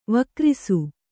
♪ vakrisu